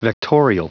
Prononciation du mot vectorial en anglais (fichier audio)
Prononciation du mot : vectorial